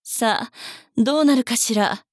大人女性│女魔導師│リアクションボイス│商用利用可 フリーボイス素材 - freevoice4creators